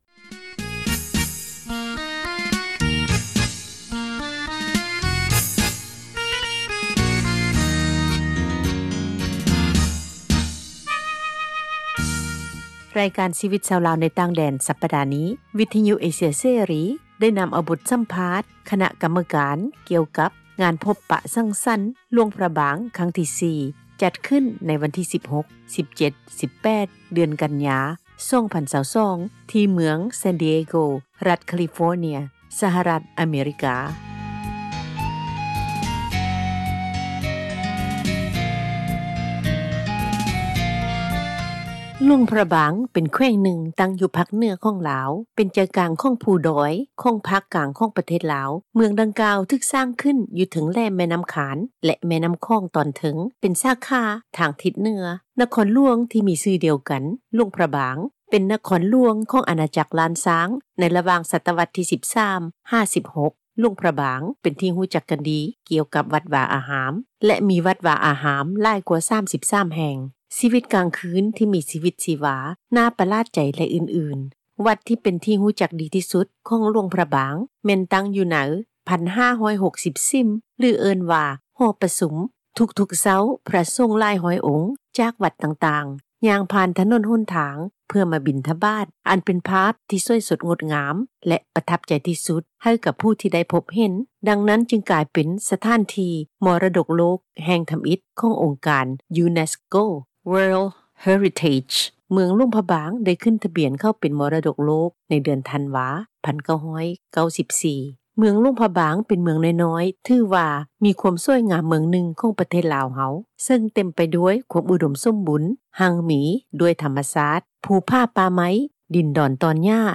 ຣາຍການ “ຊີວິຕລາວ ໃນຕ່າງແດນ” ສັປດານີ້, ວິທຍຸເອເຊັຽເສຣີ ໄດ້ນຳເອົາ ບົດສັມພາດ ຄະນະກັມມະການ ກ່ຽວກັບ ງານພົບປະສັງສັນ ຫລວງພຣະບາງ ຈາກທົ່ວໂລກ, ເປັນຕົ້ນແມ່ນ ສະຫະຣັຖ ອາເມຣິກາ, ການາດາ, ຝຣັ່ງເສດ, ອັອສເຕຣເລັຽ, ຄັ້ງທີ 4 ຈັດຂຶ້ນ ໃນວັນທີ 16th,17th,18th ເດືອນກັນຍາ 2022 ທີ່ເມືອງ ແຊນ ດີເອໂກ, ຣັຖ ແຄລີຟໍເນັຽ, ສະຫະຣັຖ ອາເມຣິກາ.